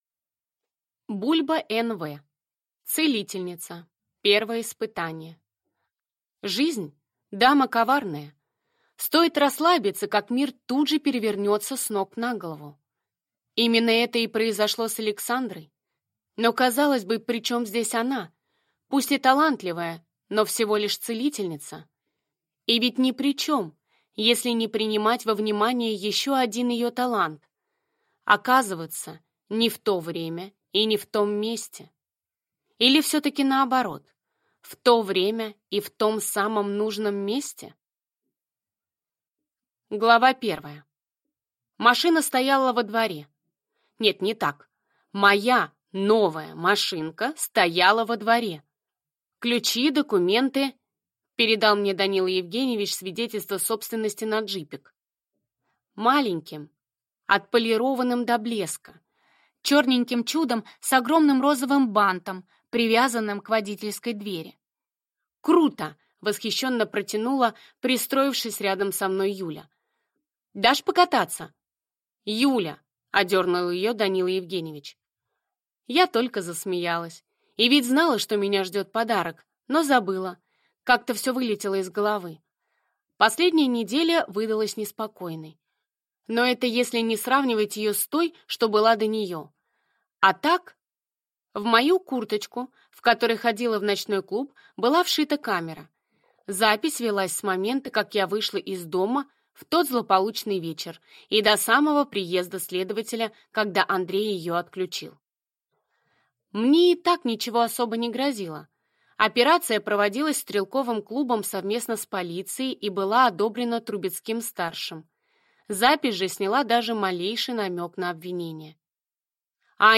Аудиокнига Целительница. Первое испытание | Библиотека аудиокниг